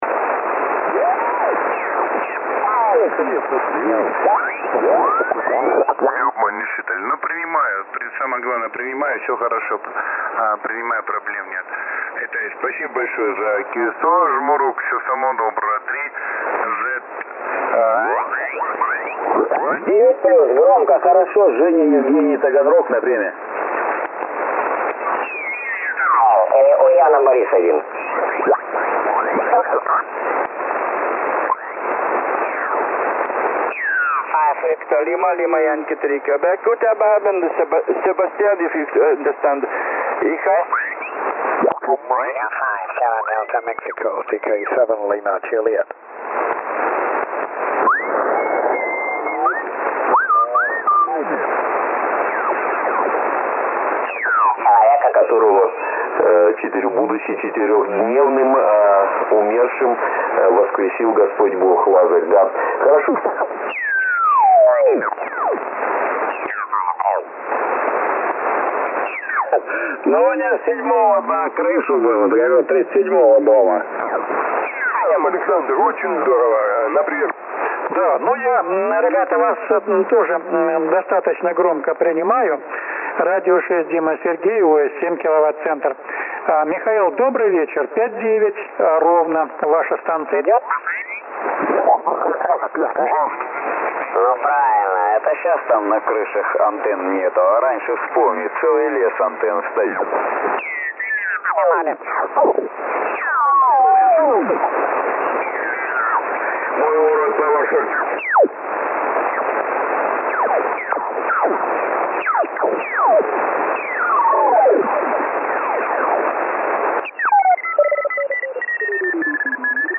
Послушал сороковку с линейного выхода, записал. Первая запись при RF0 и PRE ON:
7mHzSSB.mp3